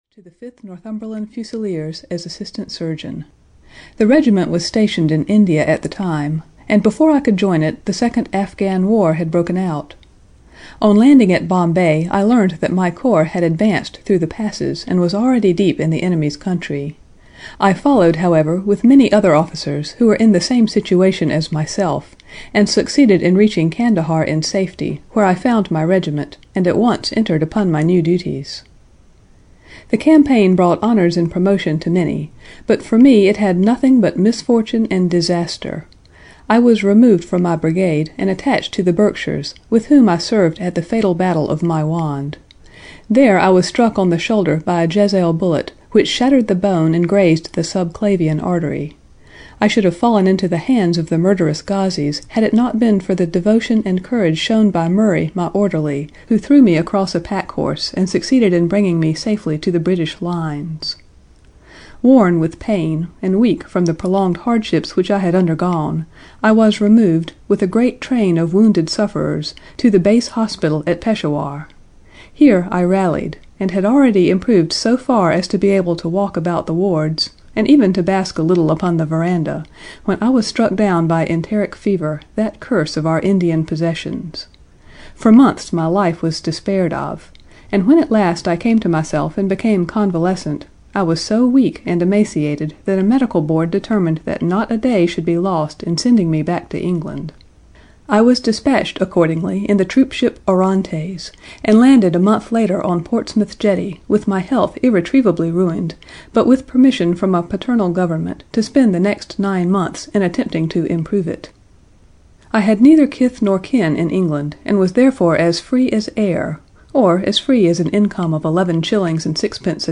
A Study in Scarlet (EN) audiokniha
Ukázka z knihy